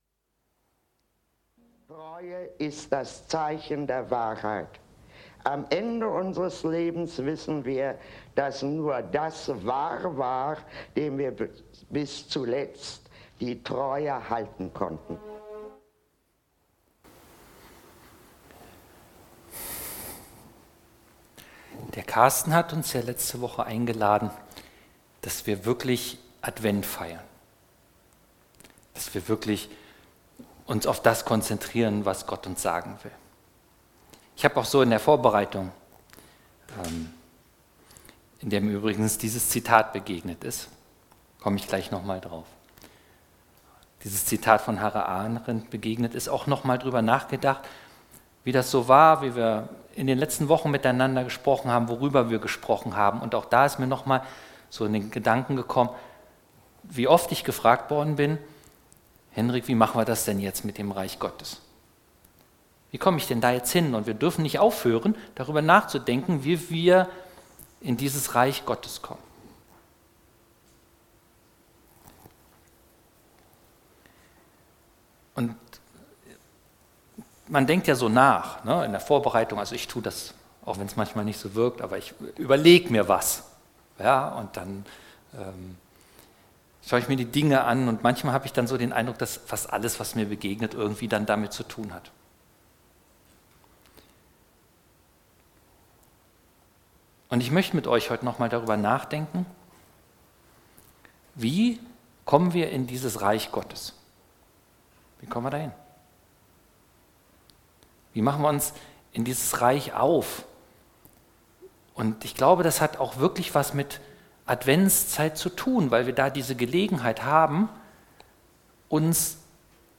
2025 in Gottesdienst Keine Kommentare 62 LISTEN